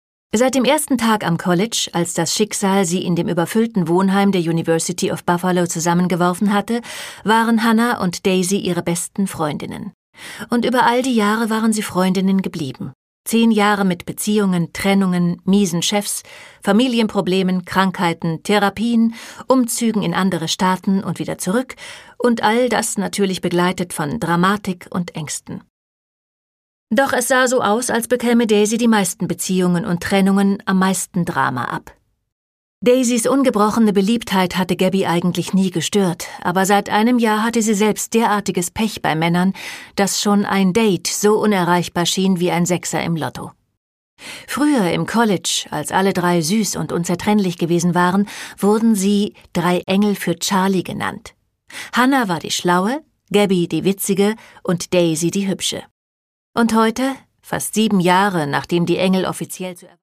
Produkttyp: Hörbuch-Download
Gelesen von: Andrea Sawatzki